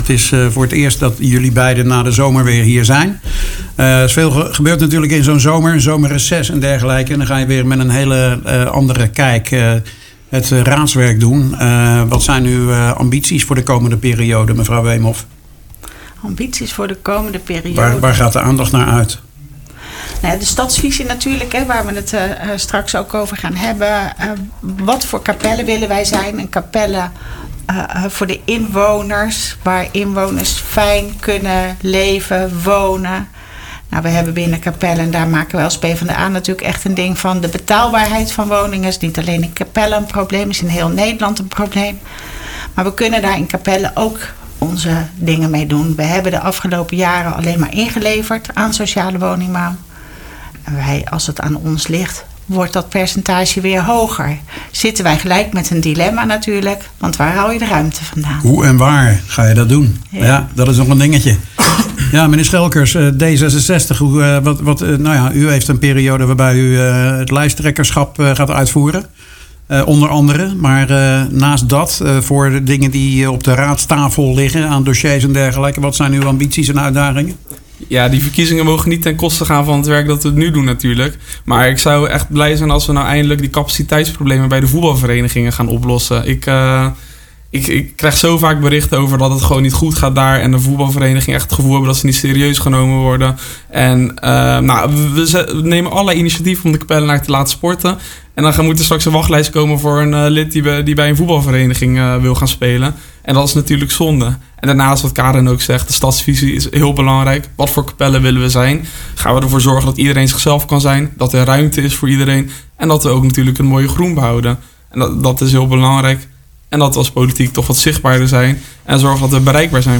Op 18 september waren de raadsleden Zinho Schelkers van D66 en Karin Weemhoff van de PvdA te gast in IJssel-nieuws.